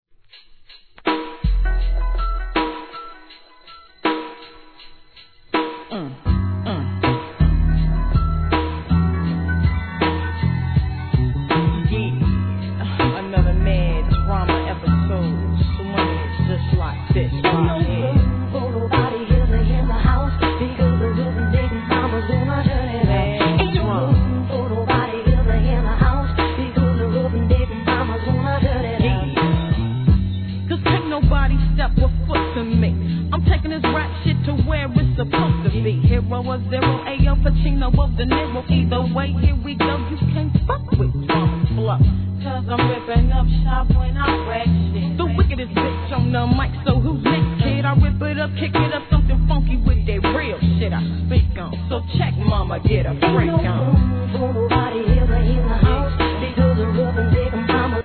HIP HOP/R&B
使いの激ﾔﾊﾞﾒﾛｳﾁｭｰﾝ!